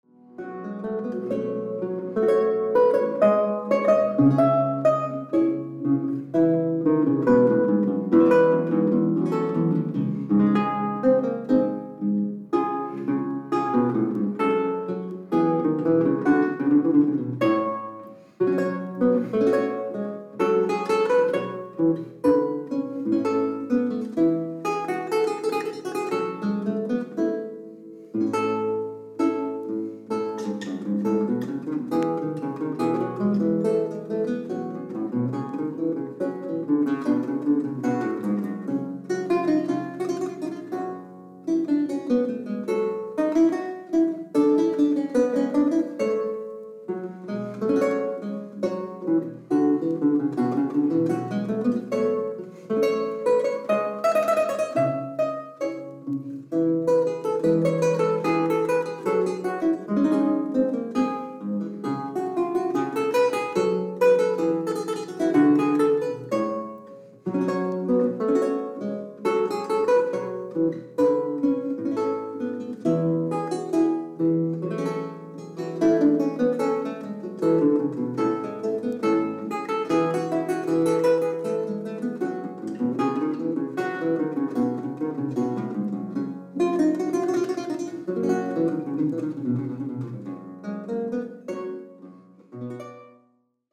Jakob Lindberg "Italian Music For Lute & Chitarrone" LP
Artist : Jakob Lindberg